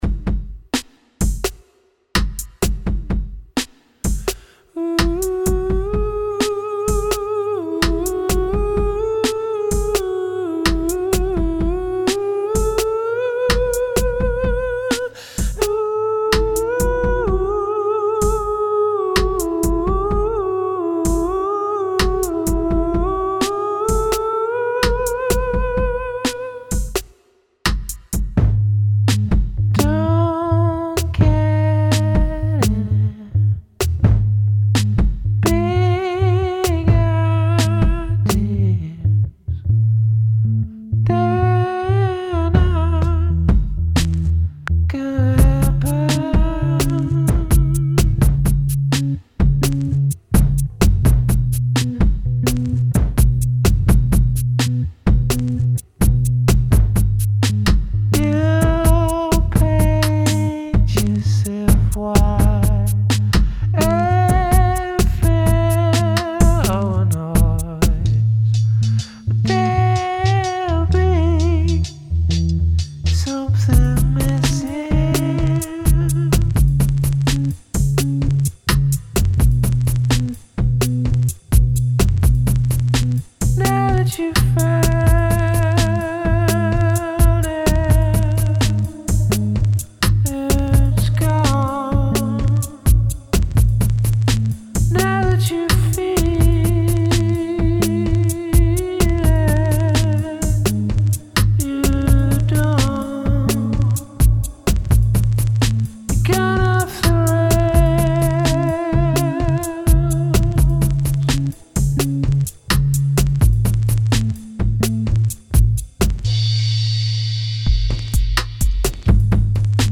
Genre: Electronic